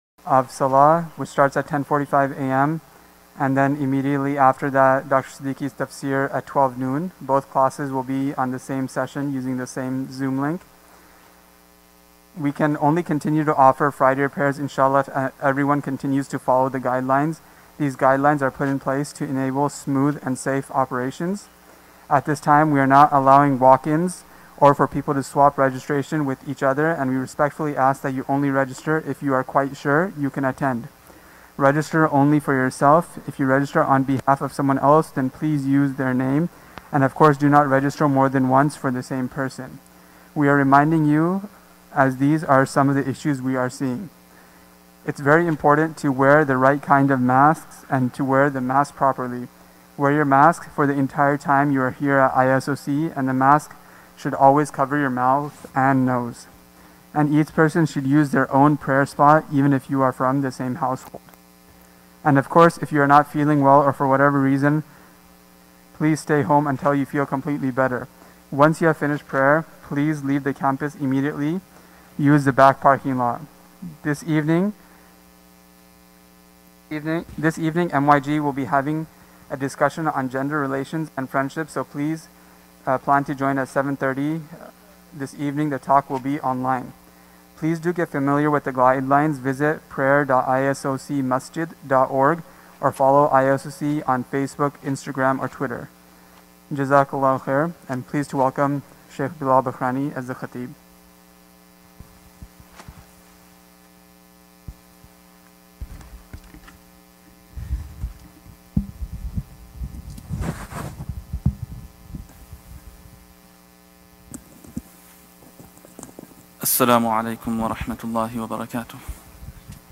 Friday Khutbah - "The Importance of Du'a"